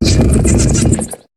Cri de Crabaraque dans Pokémon HOME.